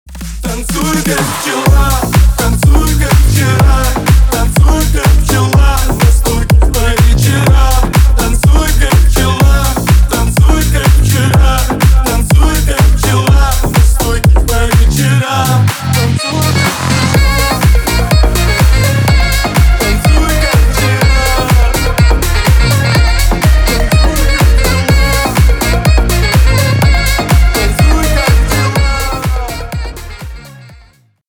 • Качество: 320, Stereo
громкие
зажигательные
Club House
Саксофон
энергичные
Энергичный клубняк с саксофоном